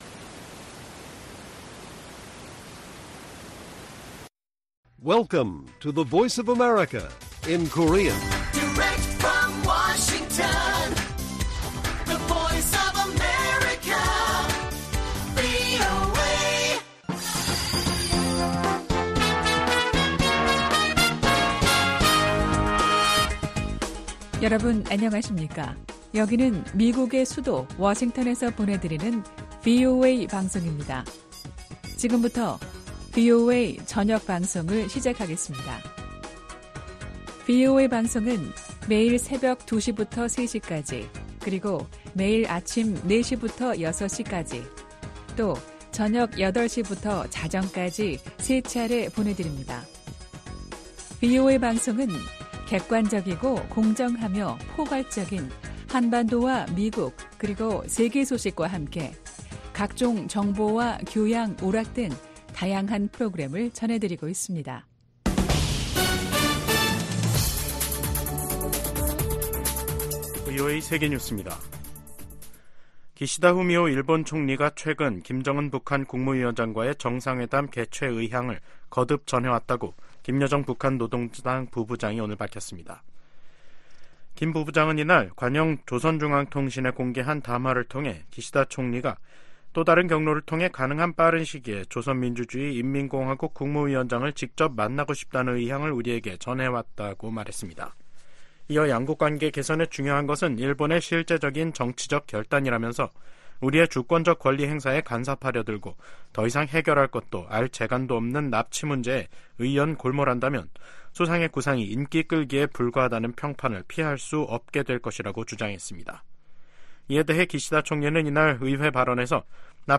VOA 한국어 간판 뉴스 프로그램 '뉴스 투데이', 2024년 3월 25일 1부 방송입니다. 러시아가 우크라이나를 향해 최소 10차례에 걸쳐 북한제 탄도미사일 40여 발을 발사했다고 로버트 우드 유엔주재 미국 차석대사가 밝혔습니다. 김여정 북한 노동당 부부장은 기시다 후미오 일본 총리로부터 정상회담 제의를 받았다고 밝혔습니다. 미 국무부는 북한에 고문 증거 없다는 중국 주장을 일축하고, 고문방지협약에 따른 송환 금지 의무 준수를 촉구했습니다.